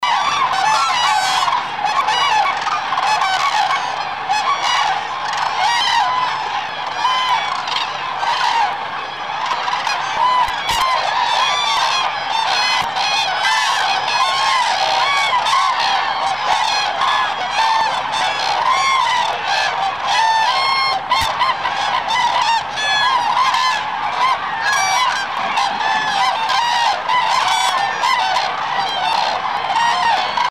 Mit ihrer enorm langen Luftröhre schmettern sie trompetenartige Rufe ins offene Gelände, die noch in 2 km Entfernung zu hören sind. Die Tonskala reicht vom schrillen Rufen bis zu dumpfen Trompetentönen.
MP3-Sound-File vom Kranichtanz  (716 kB)
kranichtanz.mp3